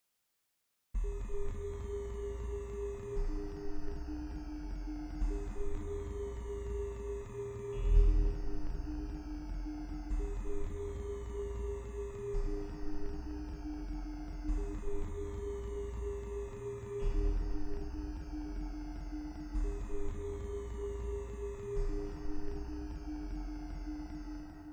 科学幻想的声音 " 航天器舱内的环境噪音1
Tag: 机械 电子 机械 未来 航天器 外星人 空间 科幻 噪音